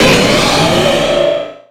Cri de Méga-Cizayox dans Pokémon X et Y.
Cri_0212_Méga_XY.ogg